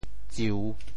咒（呪） 部首拼音 部首 口 总笔划 8 部外笔划 5 普通话 zhòu 潮州发音 潮州 ziu3 文 中文解释 咒 <动> (呪俗作咒。